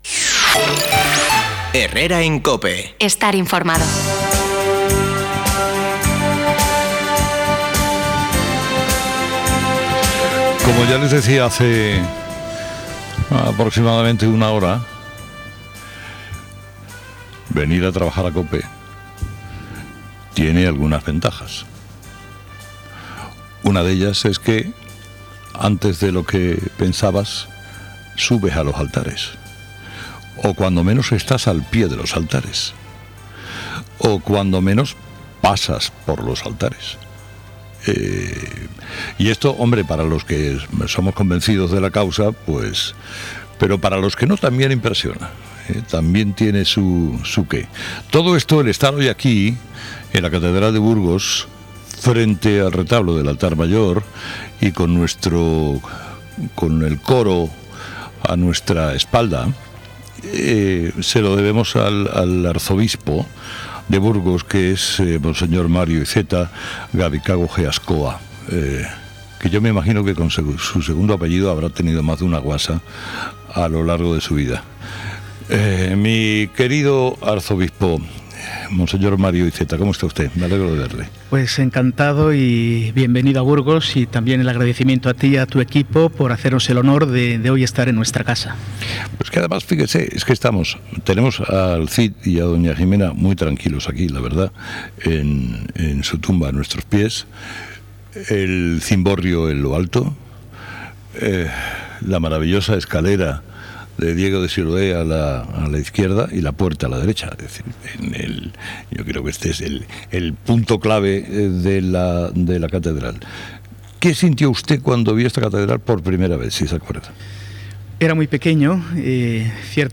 Carlos Herrera se ha desplazado hasta la Catedral para realizar su programa de COPE con motivo del VIII Centenario del templo gótico.
carlos-herrera-entrevista-mario-iceta.mp3